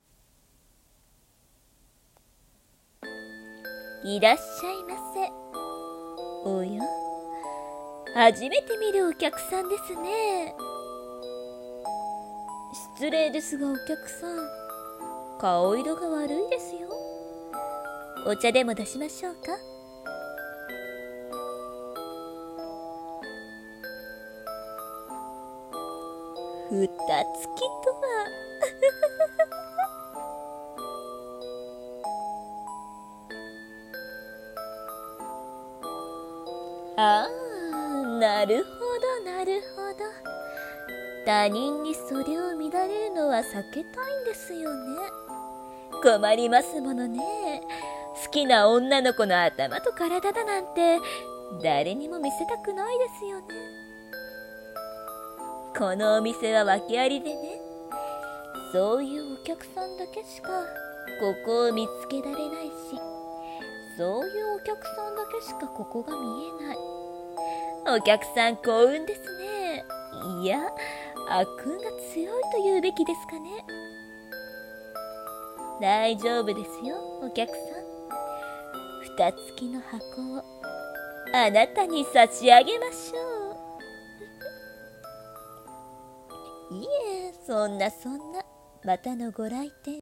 【声劇】✕✕を売る店①【闇台本】